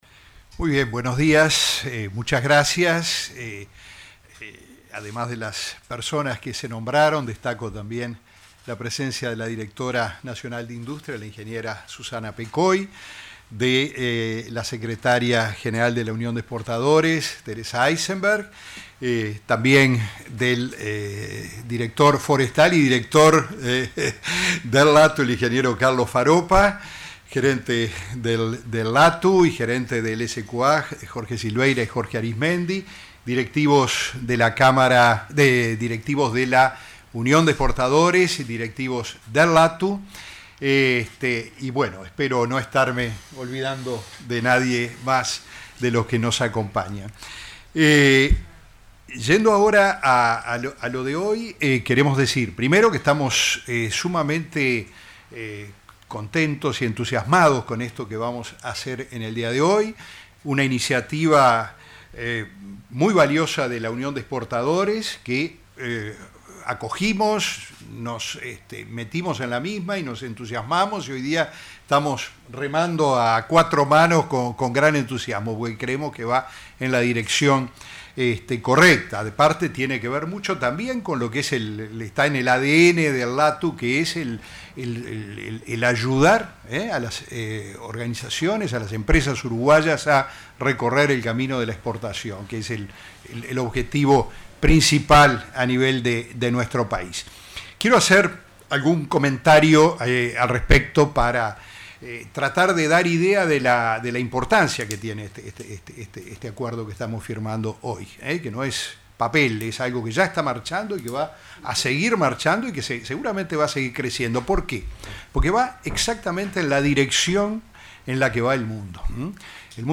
Conferencia de prensa por convenio de cooperación para certificar empresas sostenibles